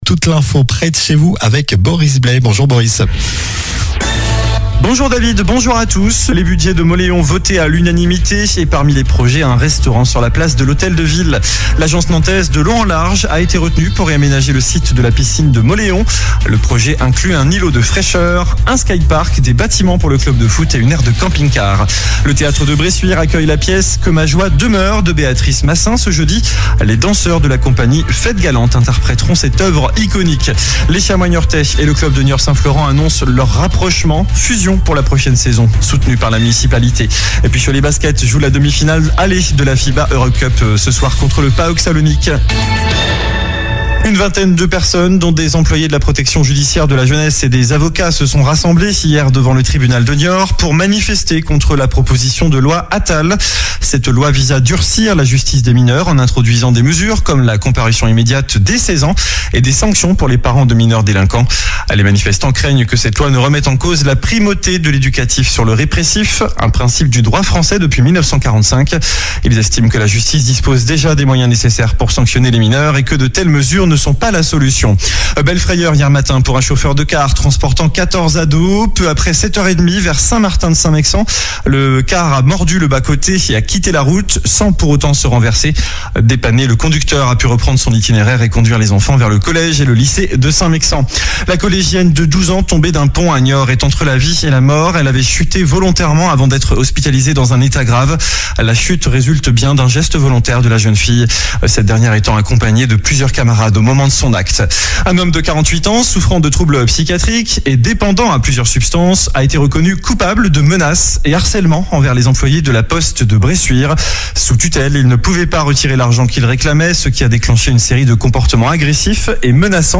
Journal du mercredi 26 mars (midi)